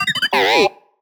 sad4.wav